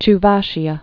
(ch-väshē-ə)